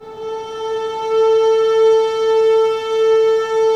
Index of /90_sSampleCDs/Roland LCDP13 String Sections/STR_Violins FX/STR_Vls Sordino